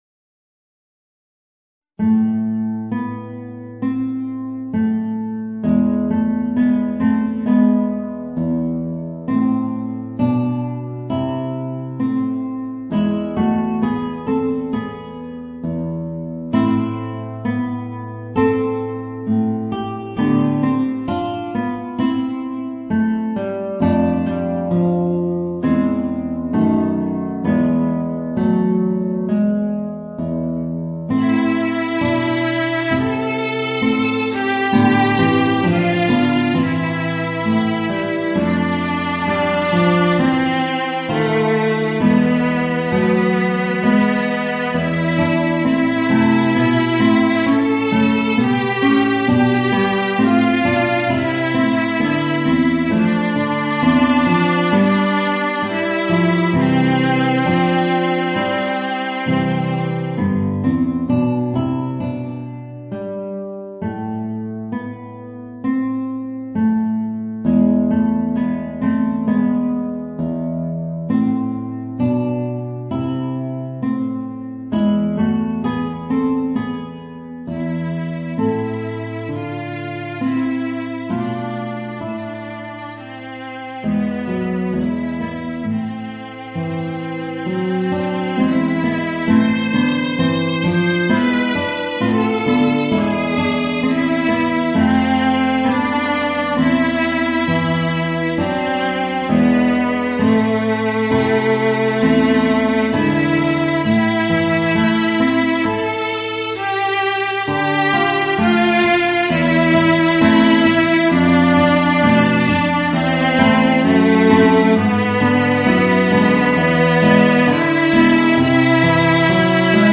Voicing: Alto Trombone and Guitar